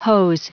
Prononciation du mot hose en anglais (fichier audio)
Prononciation du mot : hose